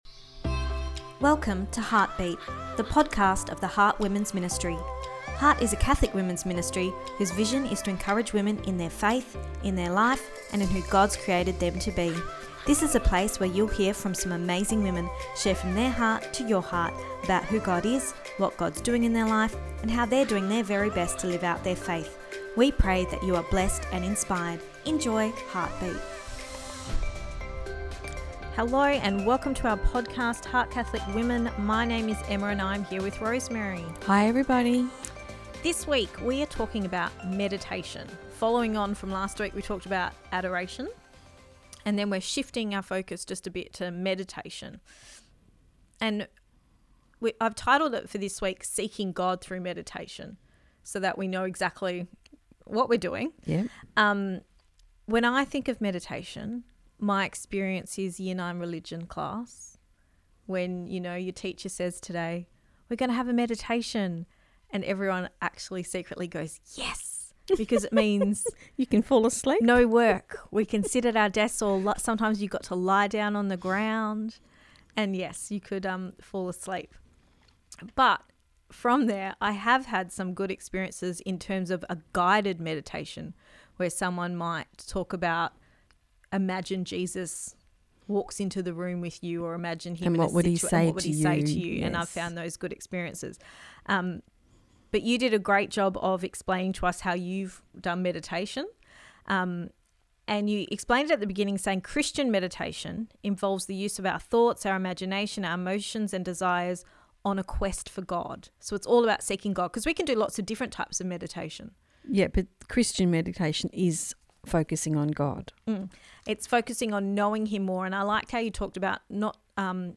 Meditation can take perseverance but St Padre Pio encourages us to start with small steps. Our chat focuses on this encouragement to include meditation in our lives.